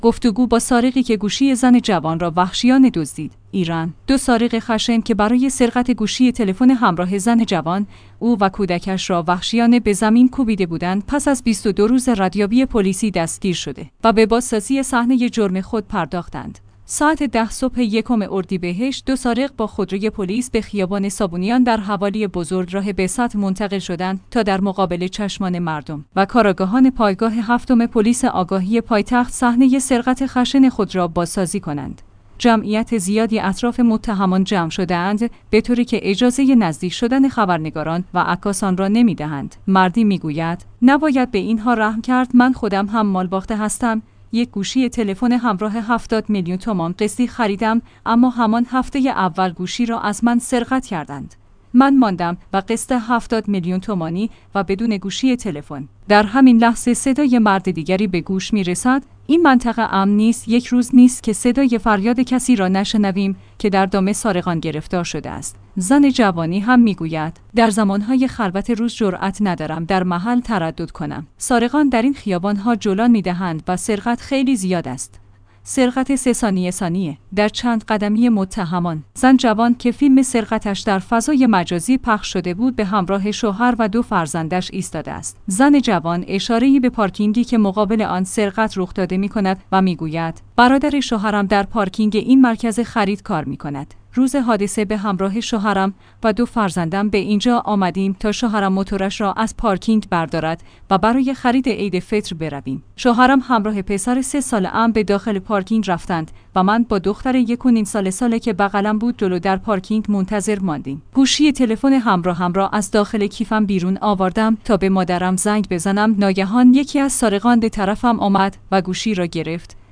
گفتگو با سارقی که گوشی زن جوان را وحشیانه دزدید